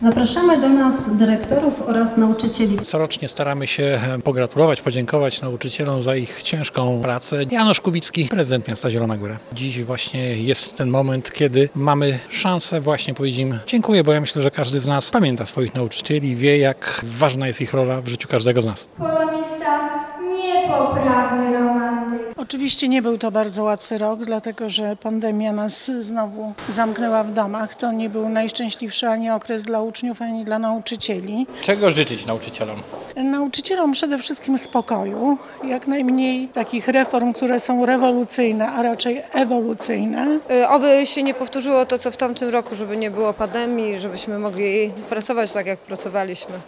Uroczystości miejskie z okazji Dnia Edukacji Narodowej odbyły się dziś w Filharmonii Zielonogórskiej.